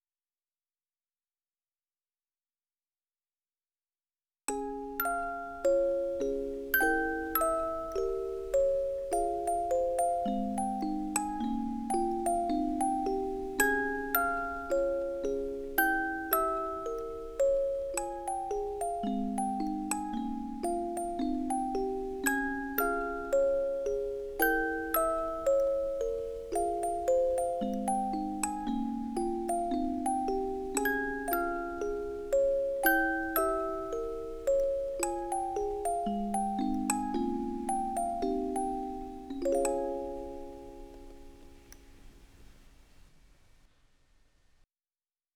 MusicBox.wav